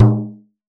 Hand Frame Drum 02.wav